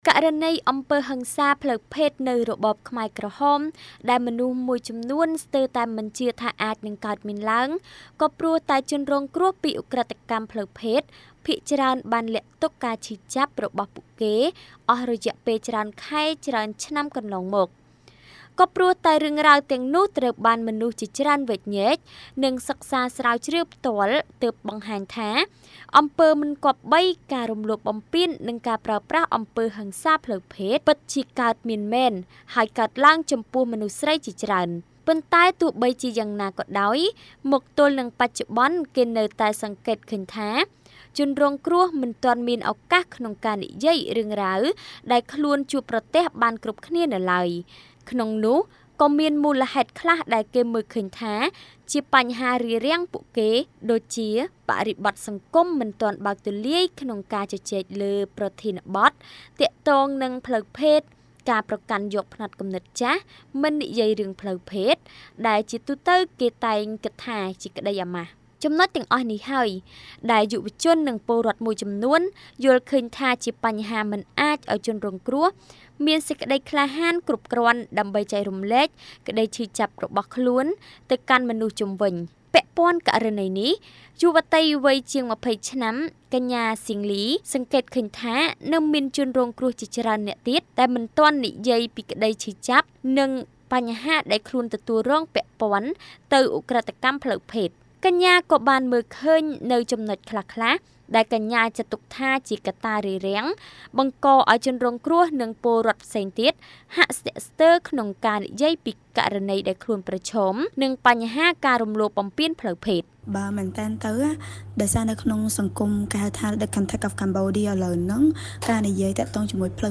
បទយកការណ៍៖ ភាគីពាក់ព័ន្ធជំរុញឱ្យជជែកលើពីហិង្សាផ្លូវភេទបន្ថែម ដើម្បីឱ្យស្រ្តីរងគ្រោះនៅរបបខ្មែរក្រហមហ៊ានចែករំលែកបន្ត